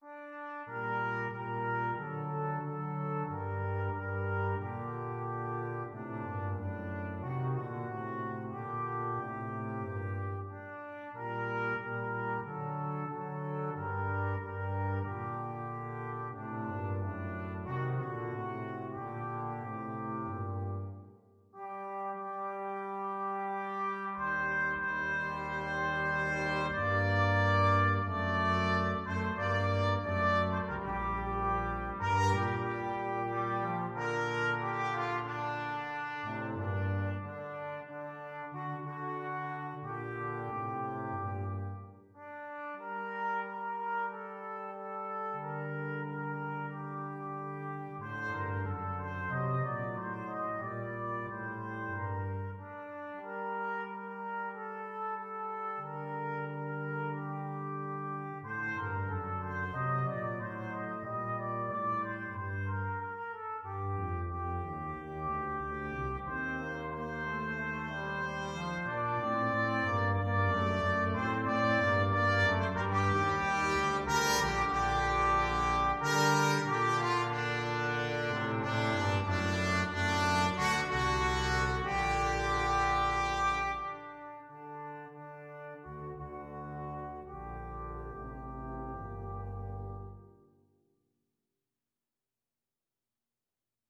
Free Sheet music for Flexible Mixed Ensemble - 3 Players
TrumpetFlute
TromboneClarinet
TubaCello
"Go Down Moses" is an American Negro spiritual.
G minor (Sounding Pitch) (View more G minor Music for Flexible Mixed Ensemble - 3 Players )
4/4 (View more 4/4 Music)
Andante cantabile ( = c. 92)
Traditional (View more Traditional Flexible Mixed Ensemble - 3 Players Music)